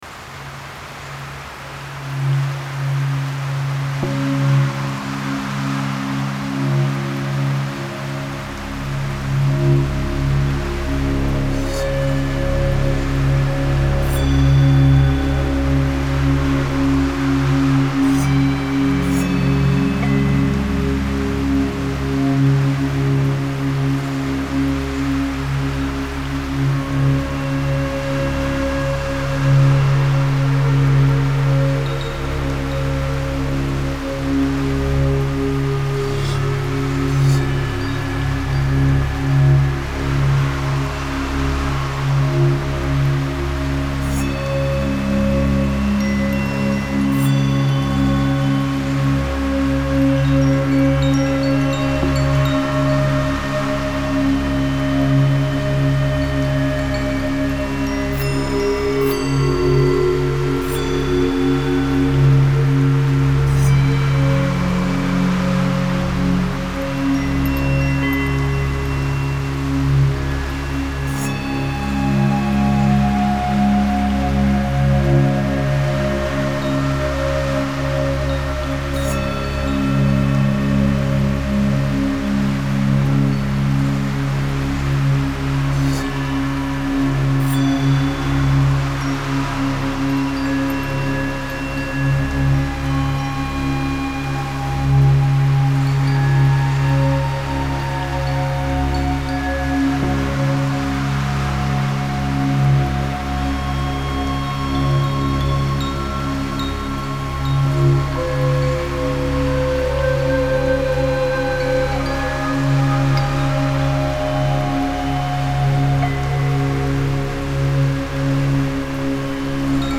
Catégorie : Musique de relaxation